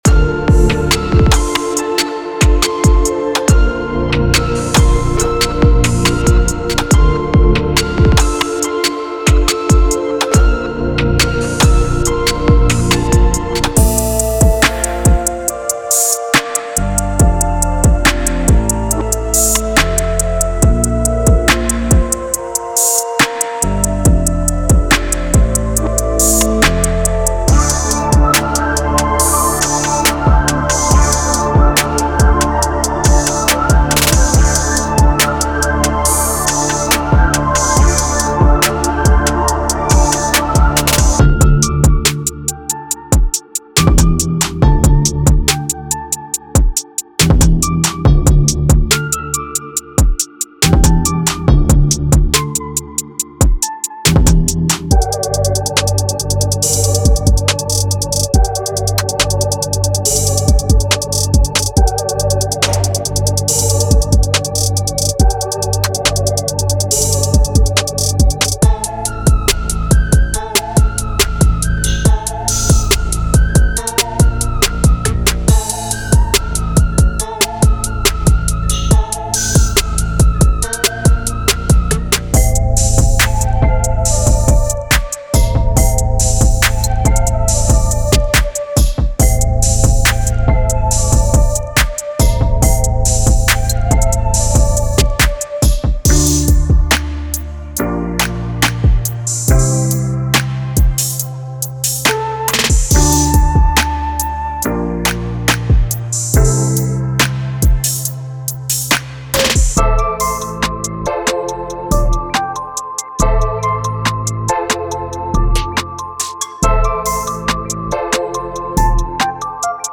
Hip-Hop / R&B Trap
With a perfect blend of classic and contemporary trap sounds, this pack is your one-stop shop for all things trap.
Inside the zip you'll find punchy kicks, crisp snares, and rolling hi-hats to give your beats that classic trap feel, as well as acollection of atmospheric, and haunting melodies along energetic chords that will take your tracks to the next level.